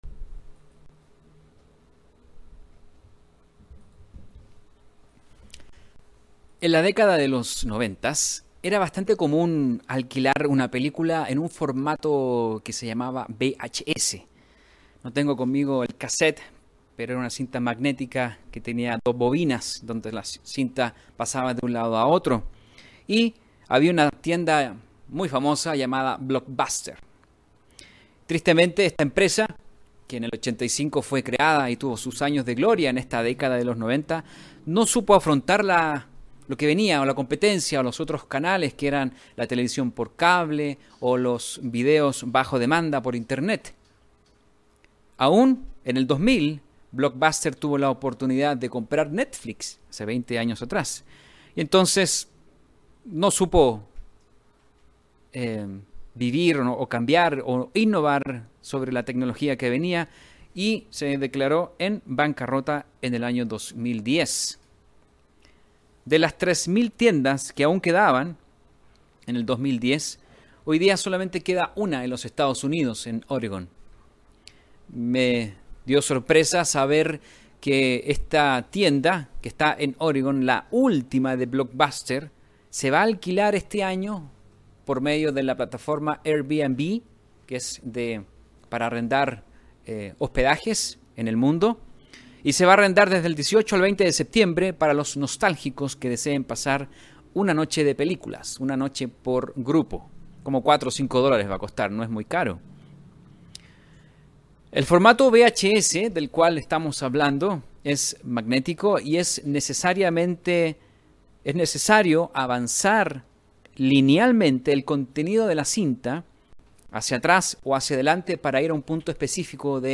La última temporada de Fiestas Santas de Dios, nos puede servir para poner en perspectiva el devenir de los eventos del tiempo del fin. Mensaje entregado el 5 de septiembre de 2020.